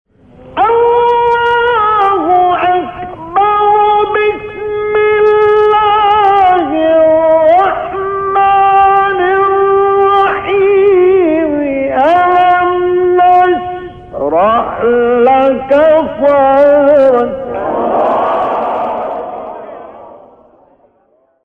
گروه فعالیت‌های قرآنی: مقاطعی صوتی از قاریان برجسته جهان اسلام که در مقام رست اجرا شده‌اند، ارائه می‌شود.
مقام رست